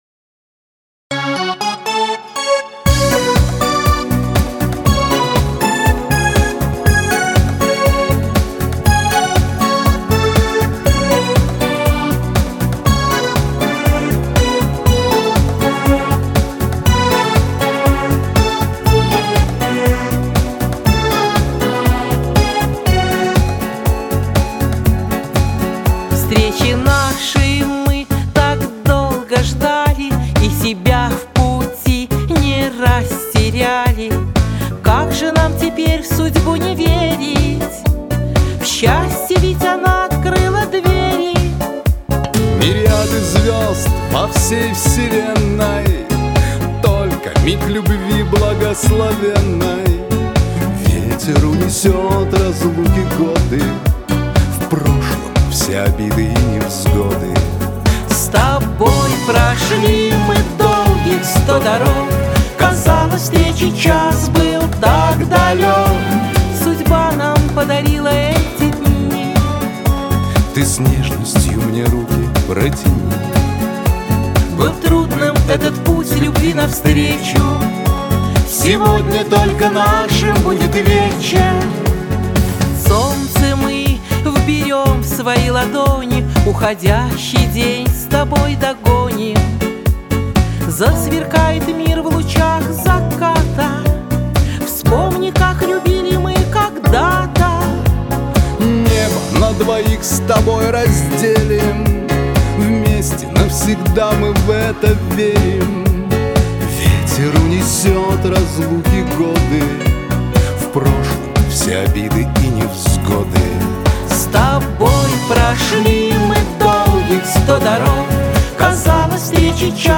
Главная » Файлы » Шансон 2016